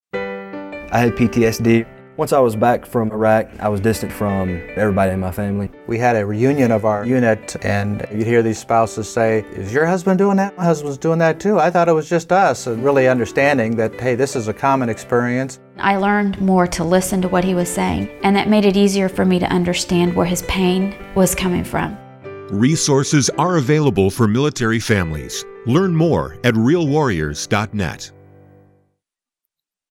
February 24, 2012Posted in: Public Service Announcement